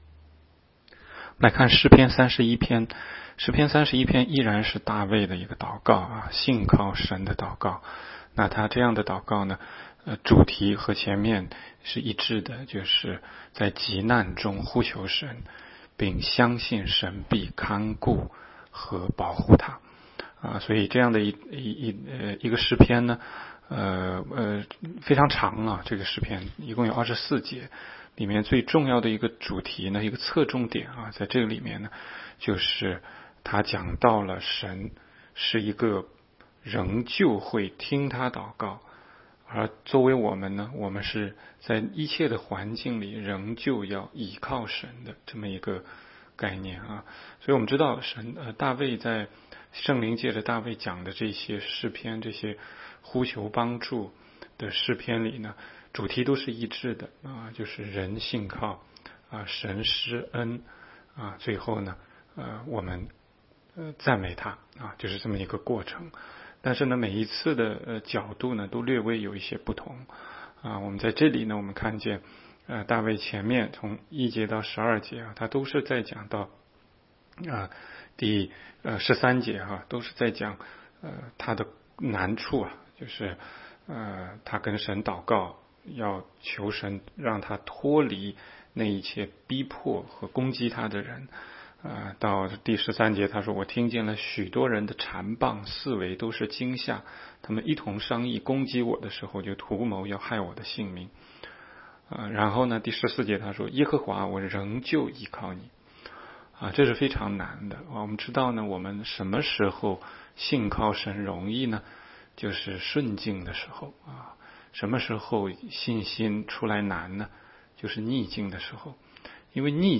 16街讲道录音 - 每日读经-《诗篇》31章